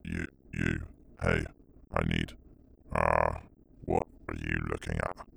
Voice Lines
Update Voice Overs for Amplification & Normalisation
Hey h Hey you i Need uhh.3.wav